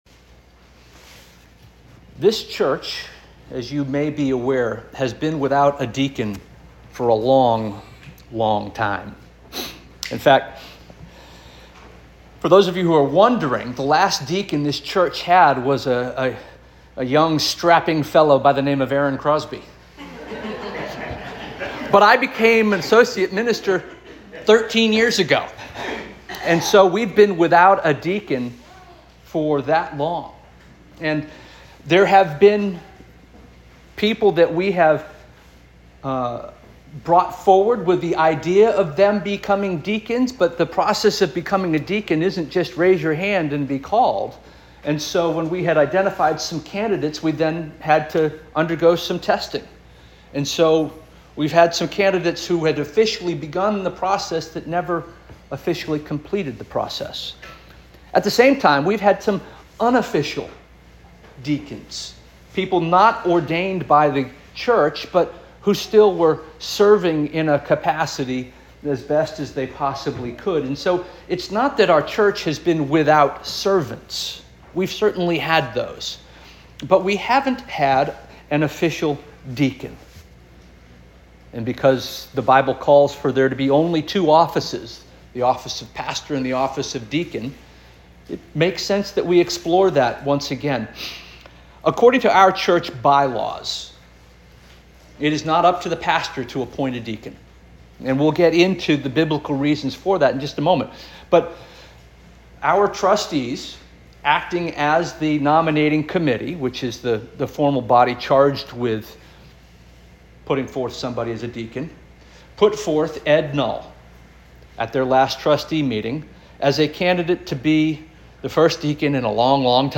May 4 2025 Sermon - First Union African Baptist Church